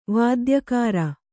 ♪ vādyakāra